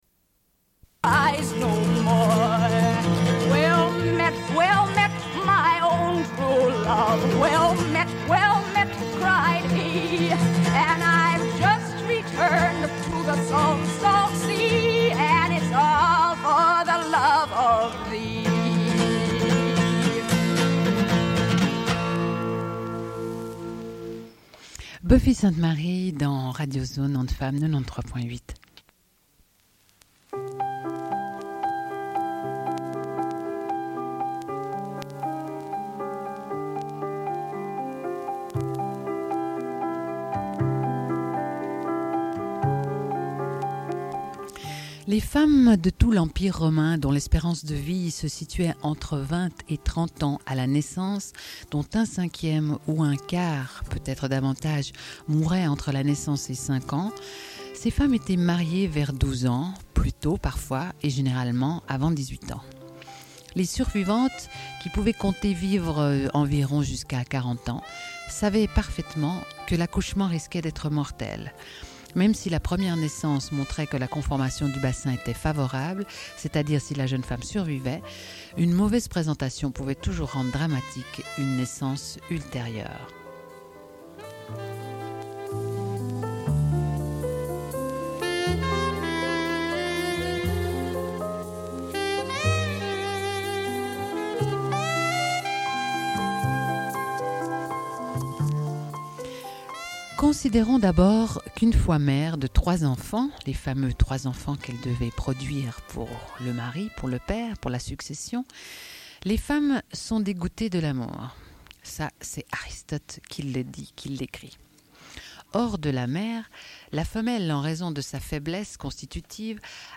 Radio Enregistrement sonore